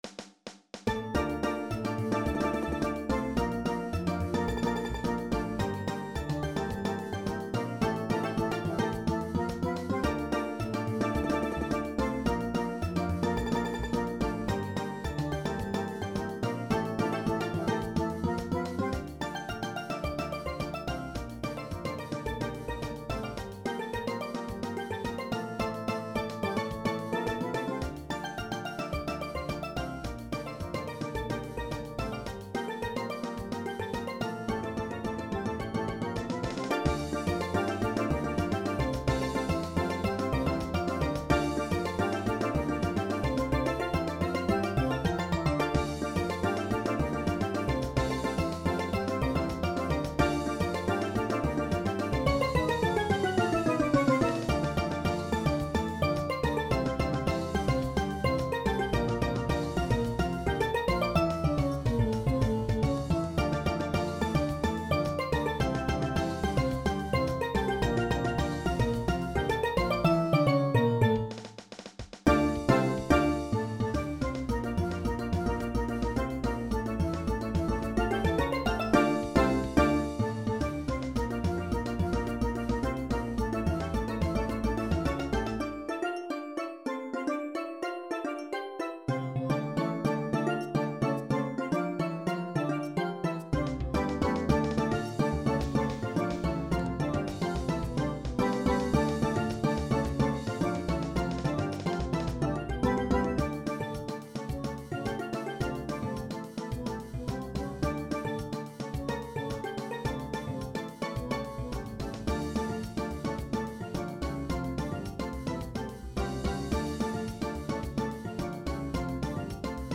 Genre: Steel Band
Lead/Tenor
Double Tenors
Guitar
Cello
Tenor-Bass
Bass
Drum Set
Engine Room 2: Brake Drum